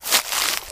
STEPS Bush, Walk 19.wav